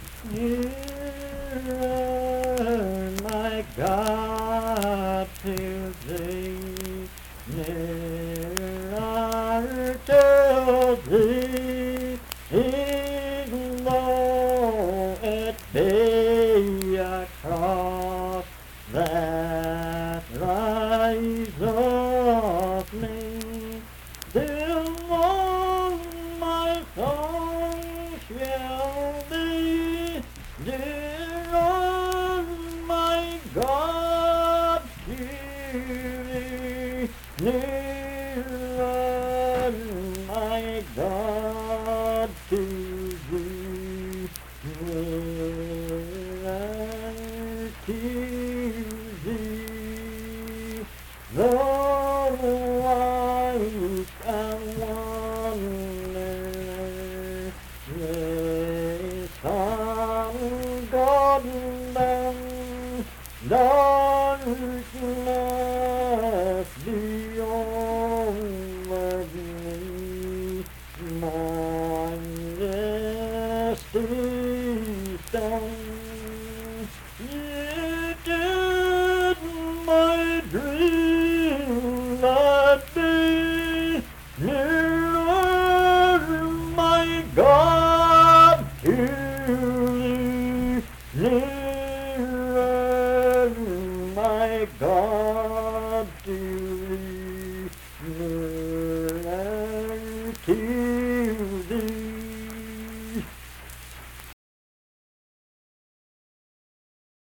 Unaccompanied vocal music
Performed in Dundon, Clay County, WV.
Hymns and Spiritual Music
Voice (sung)